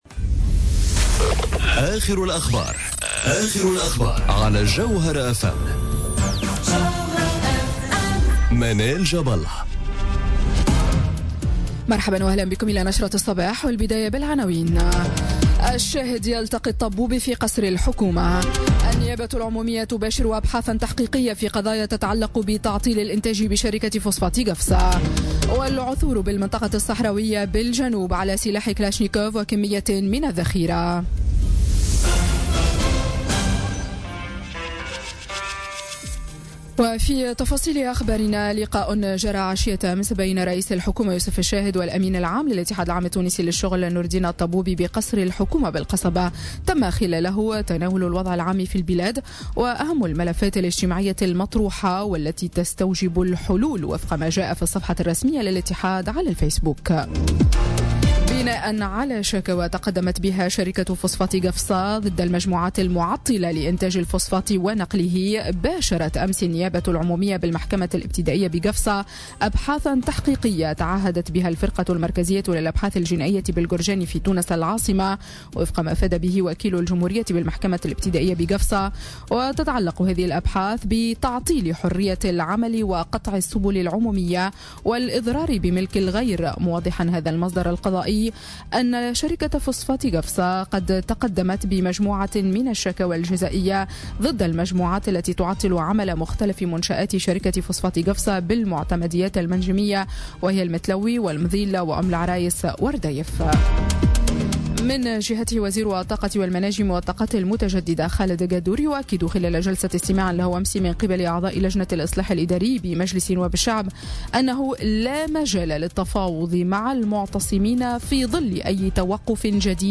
نشرة أخبار السابعة صباحا ليوم الثلاثاء 6 مارس 2018